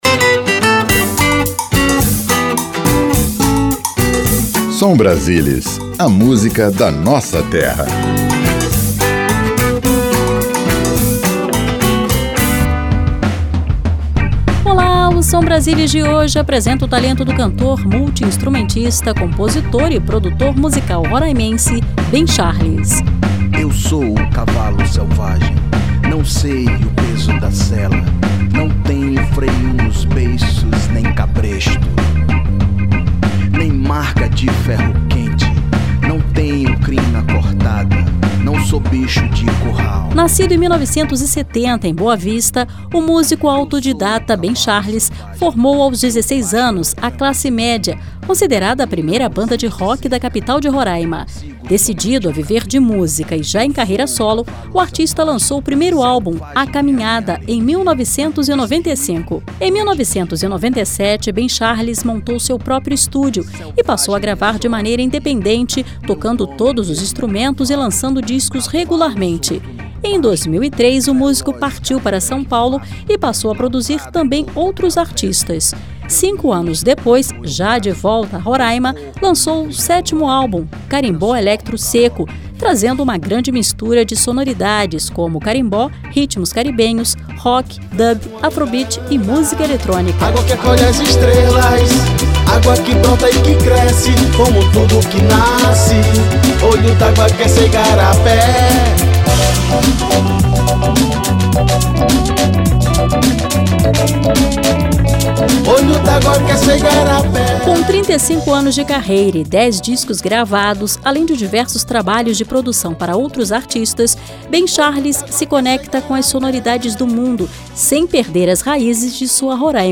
Programete musical que apresenta artistas de cada estado da federação.